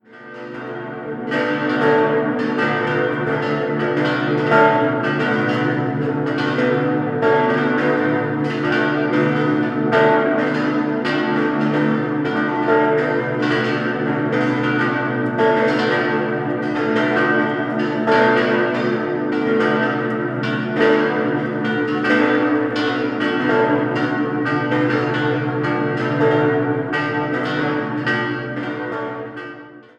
Die Weihe erfolgte dann 1911. 6-stimmiges Geläut: a°-h°-d'-fis'-a'-h' Alle Glocken wurden von Oberascher in Salzburg gegossen: 3-6 im Jahr 1952, Nr. 2 1957 und die große Glocke schließlich 1962.